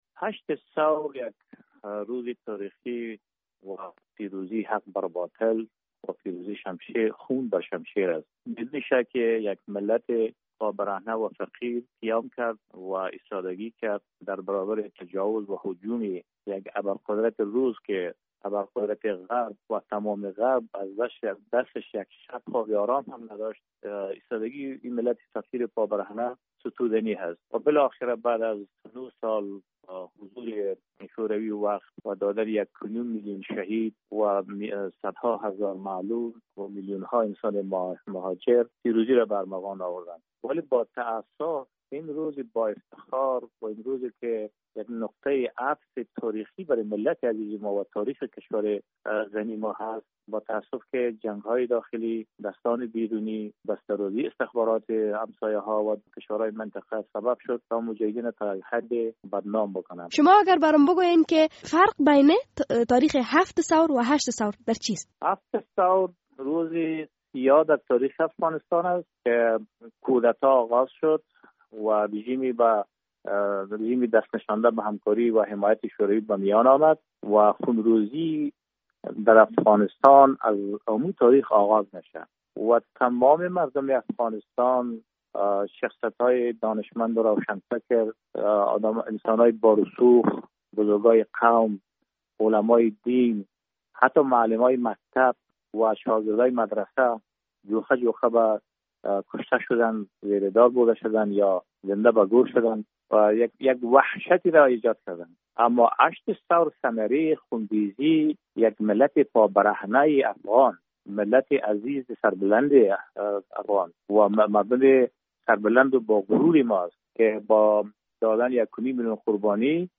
مصاحبه - صدا
مصاحبه با عطا محمد نور